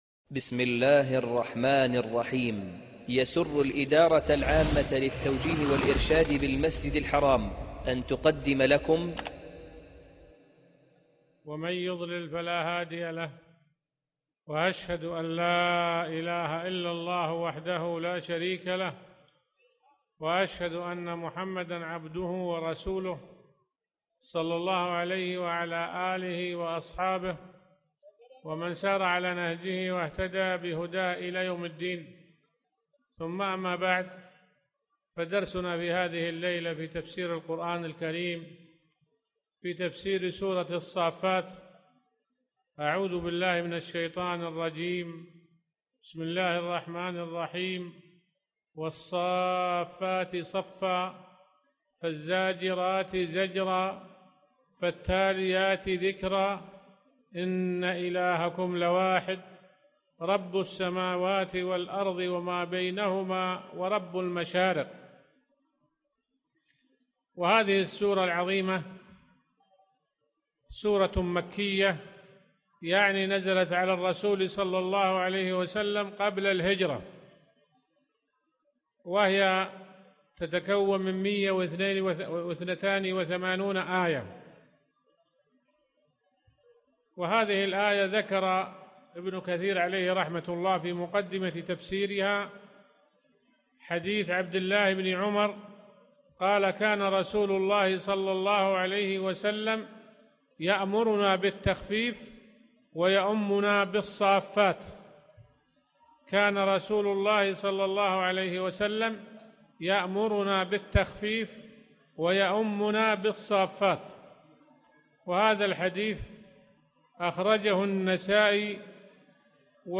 الدرس 1 ( مختصر تفسير ابن كثير